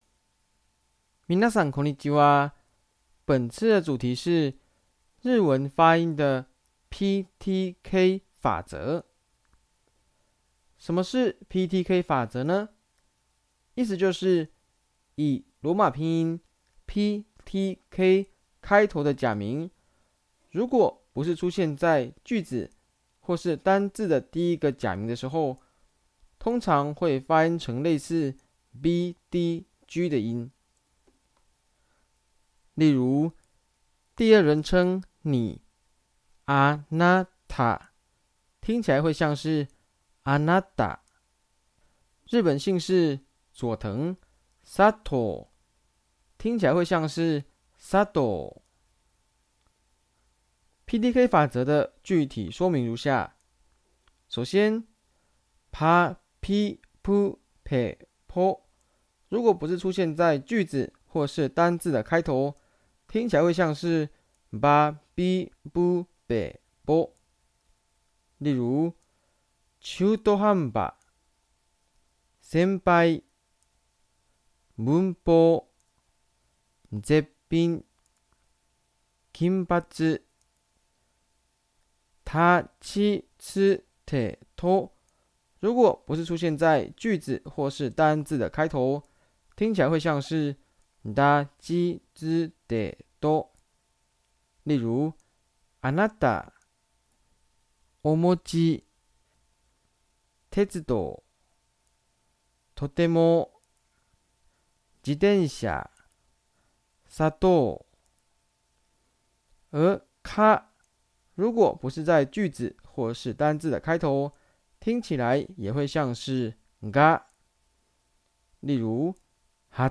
聲音解說：